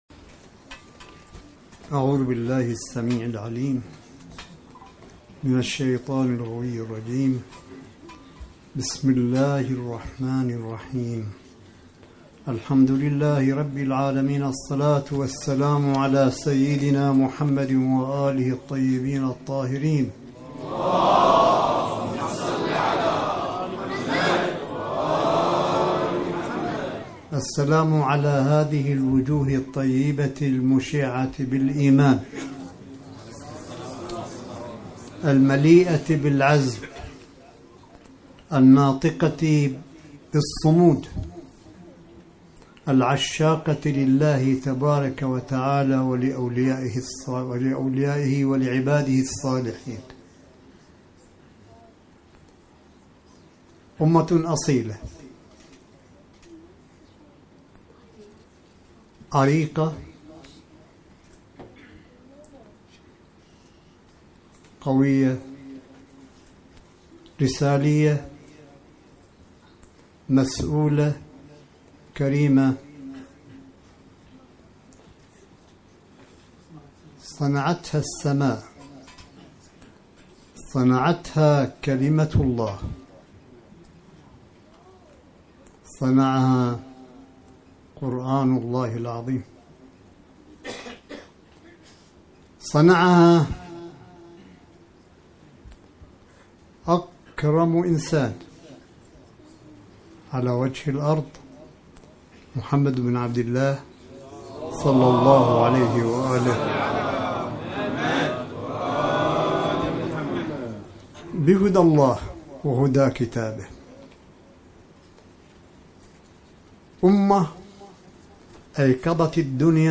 ملف صوتي لكلمة سماحة آية الله الشيخ عيسى أحمد قاسم حفظه الله بمناسبة مولد الصديقة الطاهرة فاطمة الزهراء (ع) في الحسينية البحرانية بمدينة قم المقدسة – 25 فبراير 2019م
كلمة-آية-الله-قاسم-بمولد-الزهراء-2019-قم-المقدسة-_-جودة-عالية-1.mp3